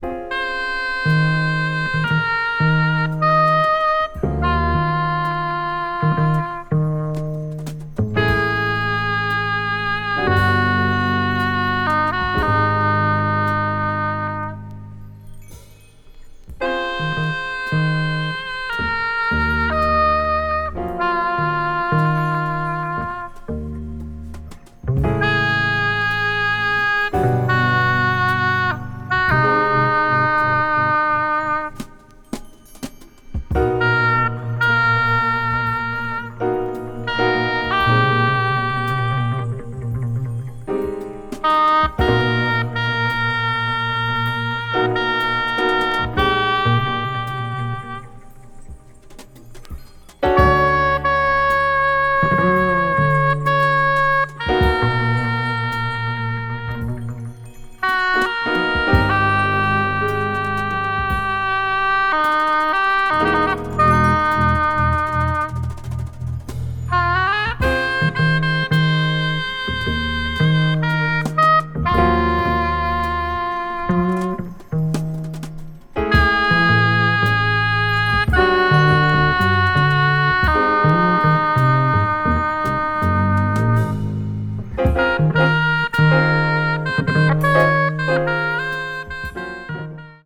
contemporary jazz   modal jazz   post bop   spritual jazz